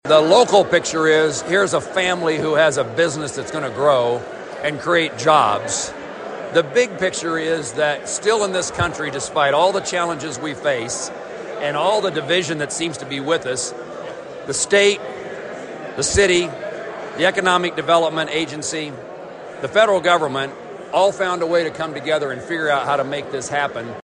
Celebration ruled the day at Fanestil Meats’ new facility in the 4700 block of US Highway 50 on Friday.
US Senator Jerry Moran says the new processing plant is the reward for a lot of hard work by a lot of people.